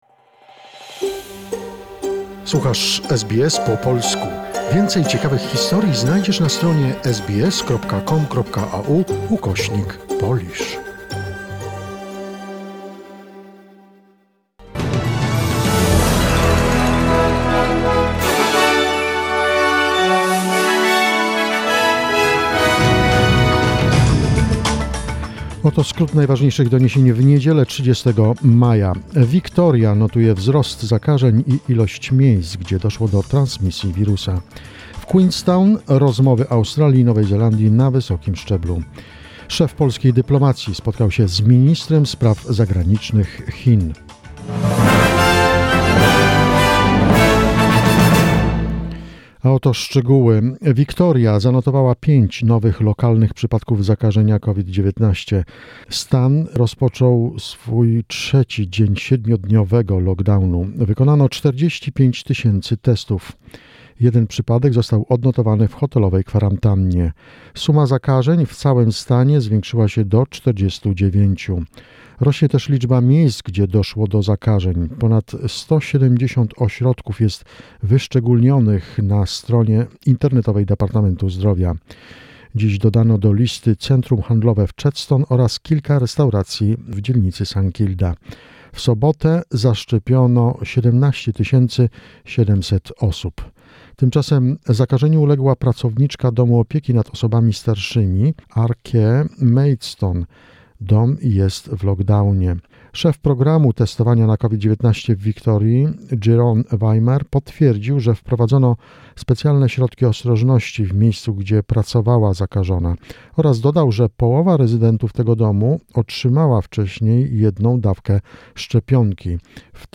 Wiadomości SBS, 30 maja 2021